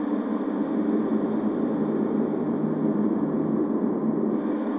Index of /musicradar/sparse-soundscape-samples/Creep Vox Loops
SS_CreepVoxLoopB-12.wav